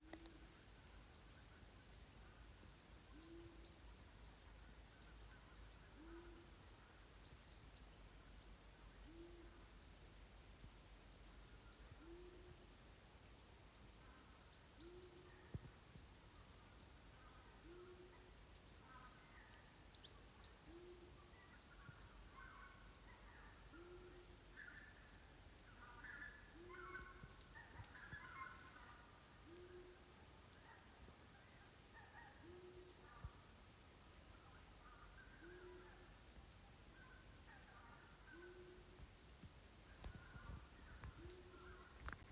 Ausainā pūce, Asio otus
StatussDzied ligzdošanai piemērotā biotopā (D)